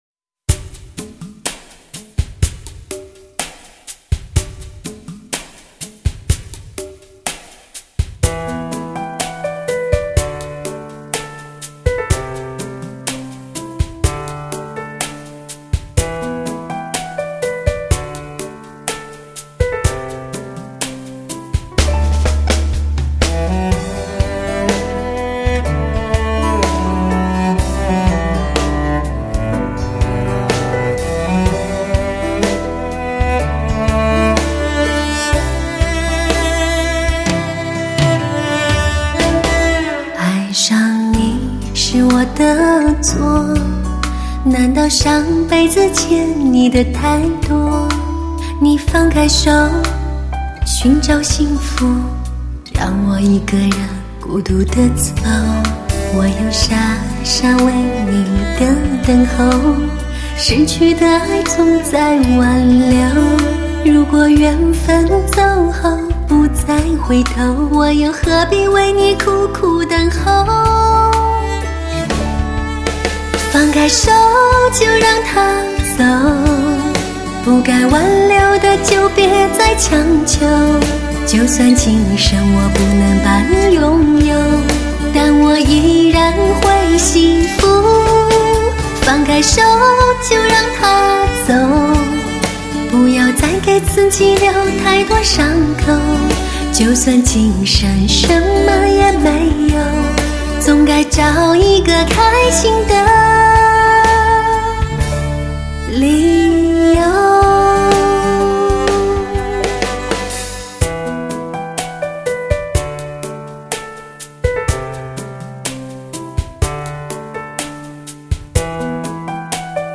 类型: HIFI试音